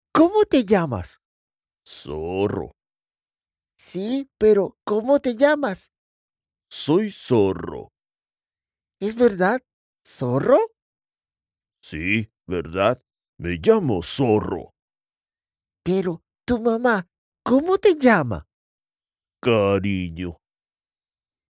Commercial, Young, Cool, Versatile, Corporate